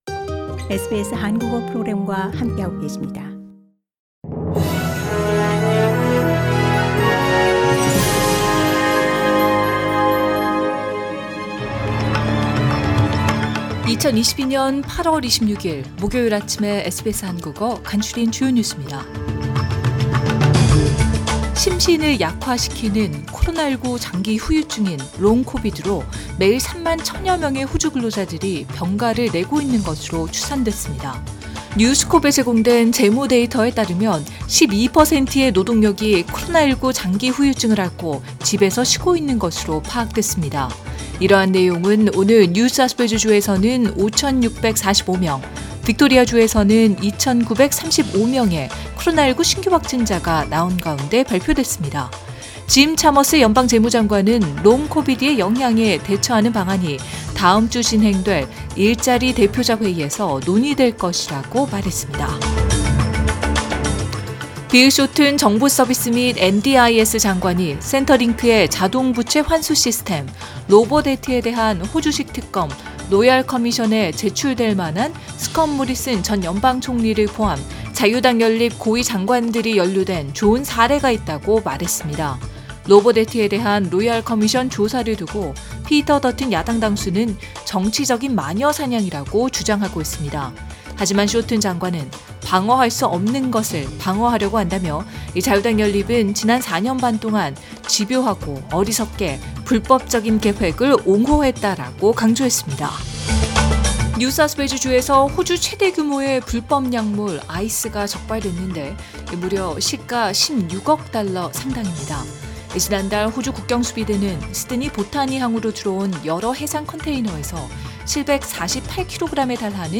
2022년 8월 26일 목요일 아침 SBS 한국어 간추린 주요 뉴스입니다.